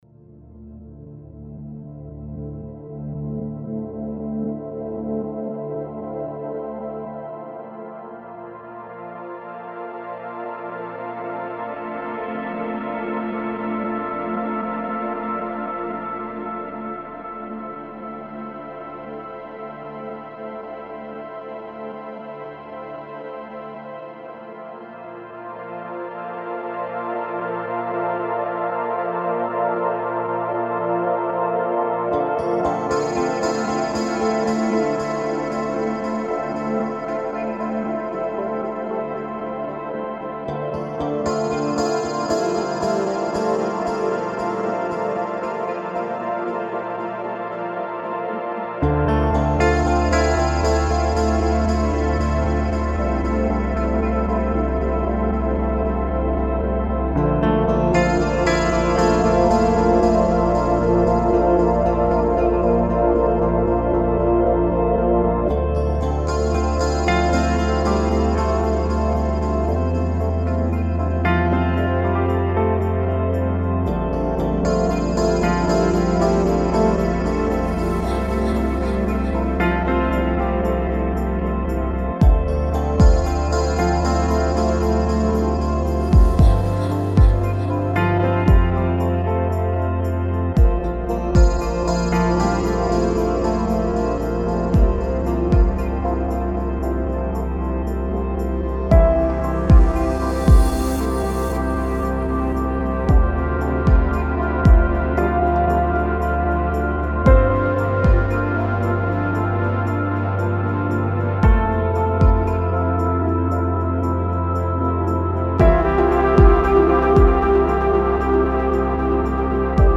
Транс музыка